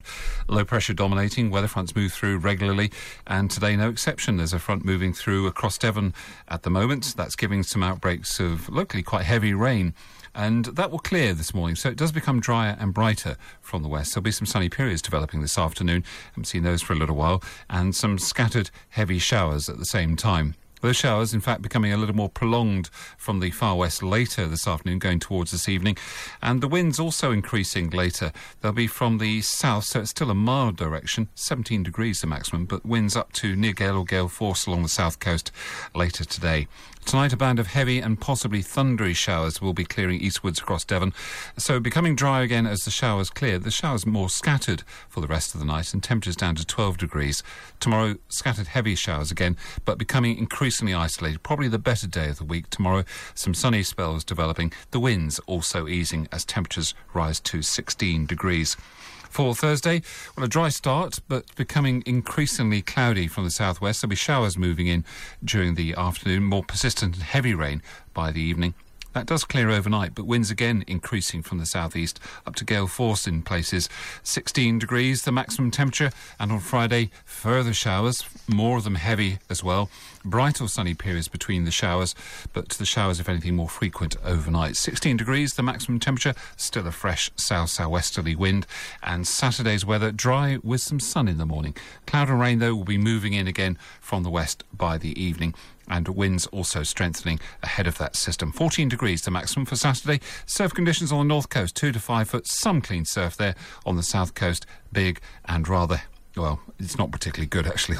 5 day forecast for Devon from 8.35AM on 22 October